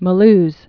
(mə-lz, mü-)